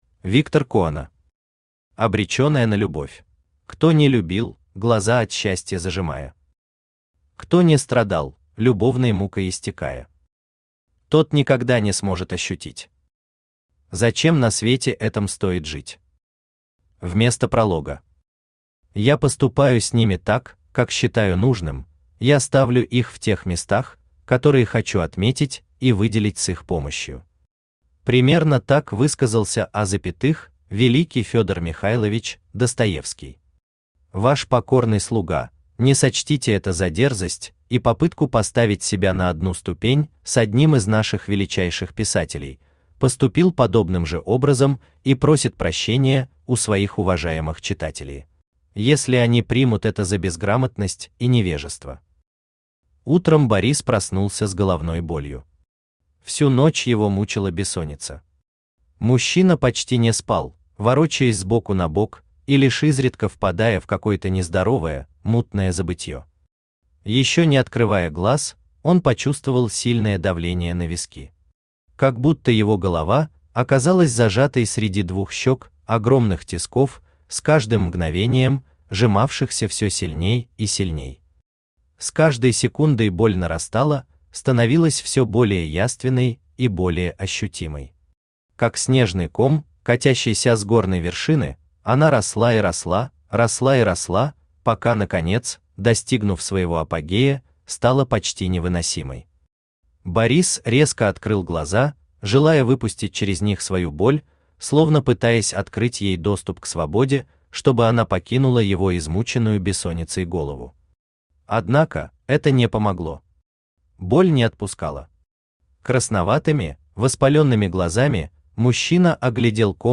Аудиокнига Обреченные на любовь | Библиотека аудиокниг
Aудиокнига Обреченные на любовь Автор Виктор Коона Читает аудиокнигу Авточтец ЛитРес.